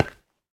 Minecraft Version Minecraft Version latest Latest Release | Latest Snapshot latest / assets / minecraft / sounds / block / bone_block / step4.ogg Compare With Compare With Latest Release | Latest Snapshot